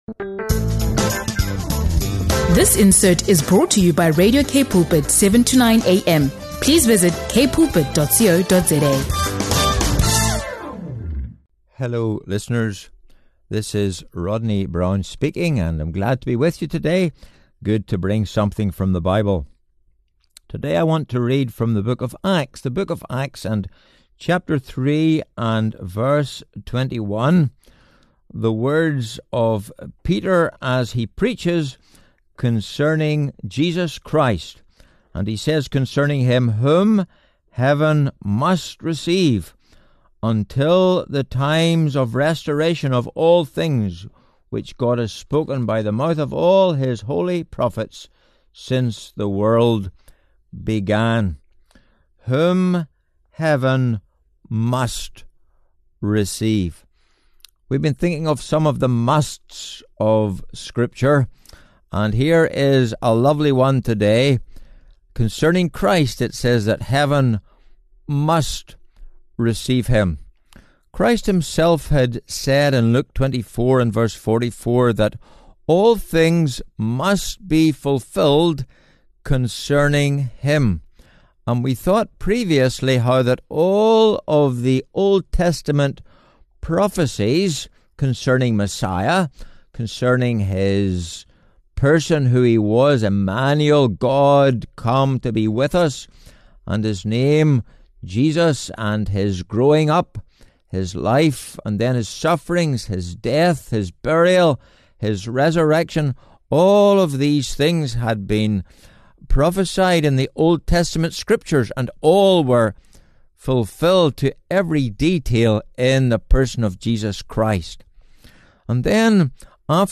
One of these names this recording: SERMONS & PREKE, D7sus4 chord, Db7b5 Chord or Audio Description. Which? SERMONS & PREKE